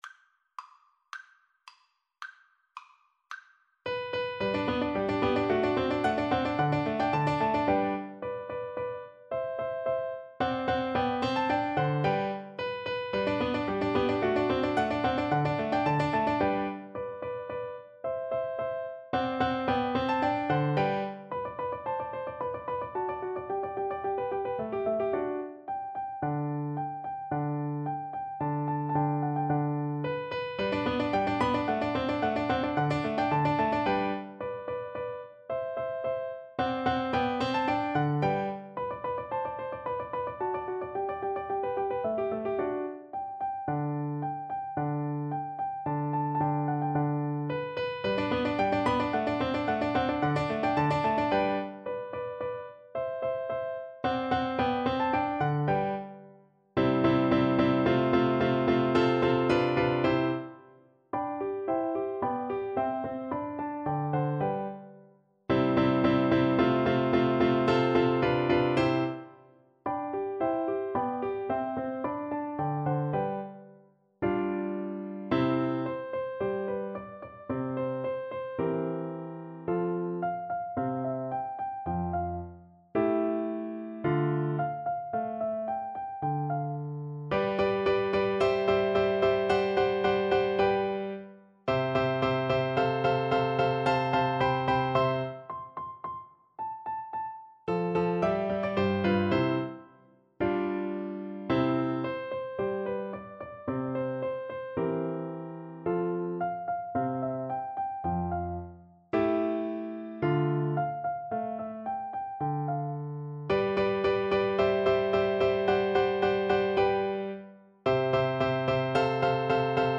Play (or use space bar on your keyboard) Pause Music Playalong - Piano Accompaniment Playalong Band Accompaniment not yet available transpose reset tempo print settings full screen
Vivace assai =110 (View more music marked Vivace)
G major (Sounding Pitch) (View more G major Music for Cello )
Classical (View more Classical Cello Music)